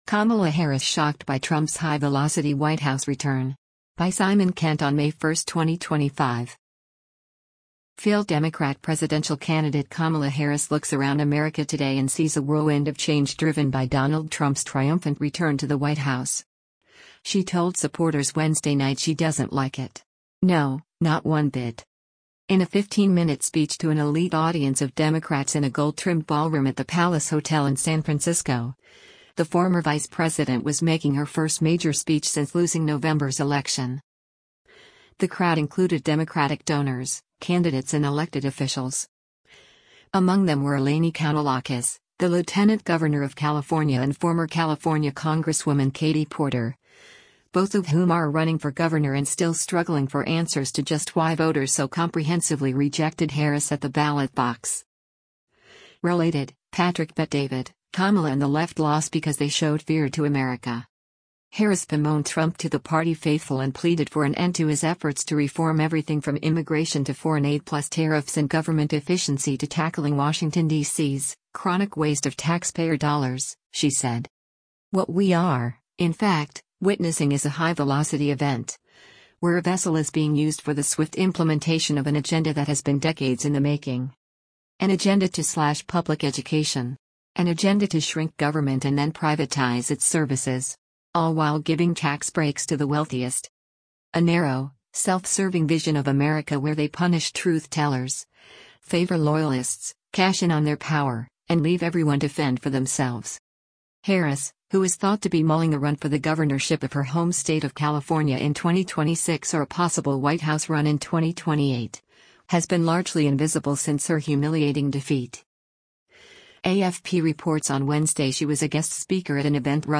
In a 15-minute speech to an elite audience of Democrats in a gold-trimmed ballroom at the Palace Hotel in San Francisco, the former vice-president was making her first major speech since losing November’s election.